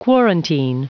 Prononciation du mot quarantine en anglais (fichier audio)
Prononciation du mot : quarantine